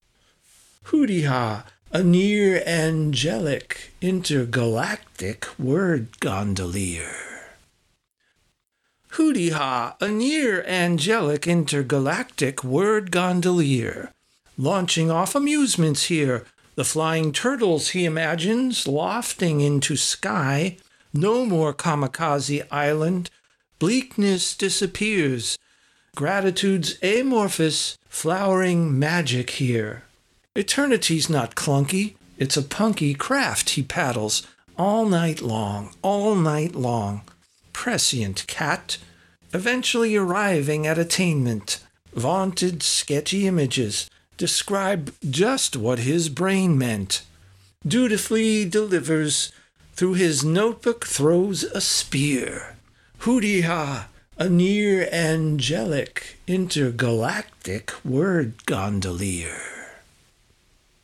Genre: raven craven spoken word.